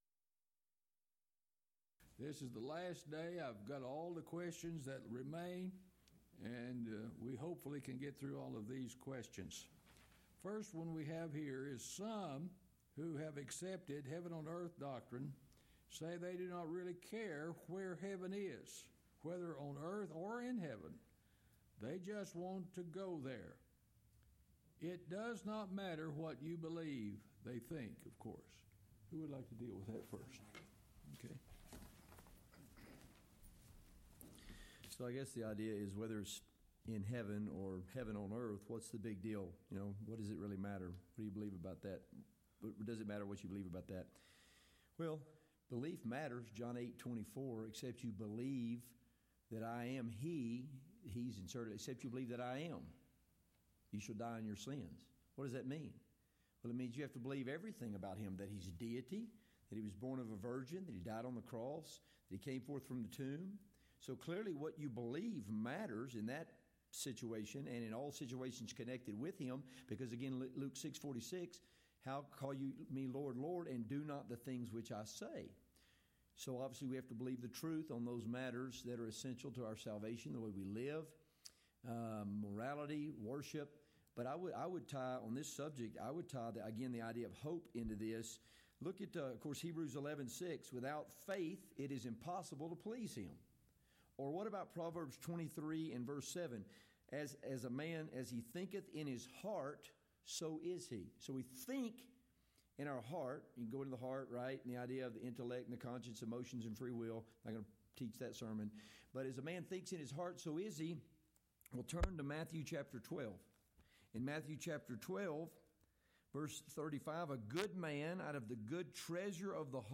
Title: Open Forum: Wednesday Speaker(s): Various Alternate File Link File Details: Series: Lubbock Lectures Event: 23rd Annual Lubbock Lectures Theme/Title: A New Heaven and a New Earth: Will Heaven Be On A "New Renovated" Earth?